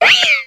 Grito de Meowstic.ogg
Grito_de_Meowstic.ogg.mp3